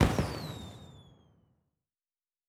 Firework (1).wav